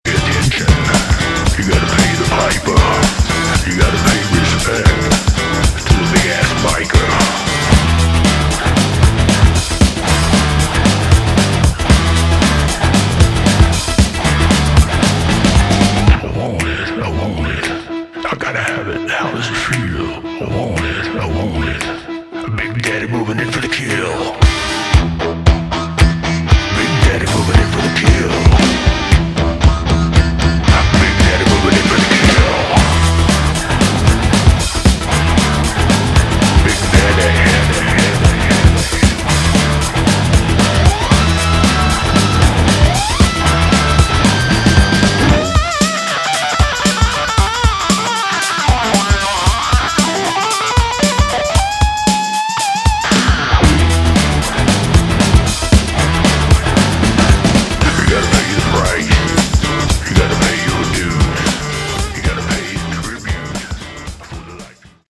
Category: Hard Rock / Boogie Rock
lead vocals, guitar
lead guitars